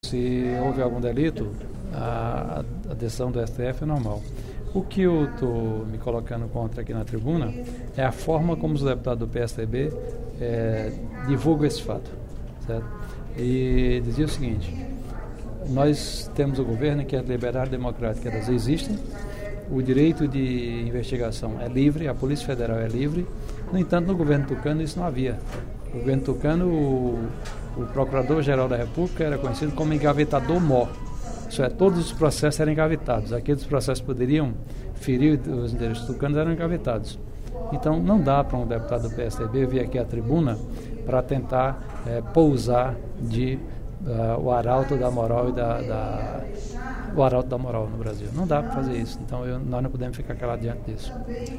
Em pronunciamento durante o primeiro expediente da sessão plenária desta quinta-feira (14/11), o deputado Professor Pinheiro (PT) comentou a decisão do Supremo Tribunal Federal (STF) de executar imediatamente a pena da maioria dos réus do mensalão. O parlamentar disse que o STF se tornou um tribunal “político, e que esquemas de corrupção anteriores ao mensalão não foram apurados com tanta celeridade”.